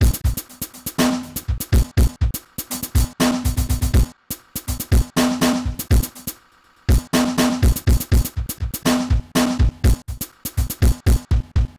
DrumLoop08.wav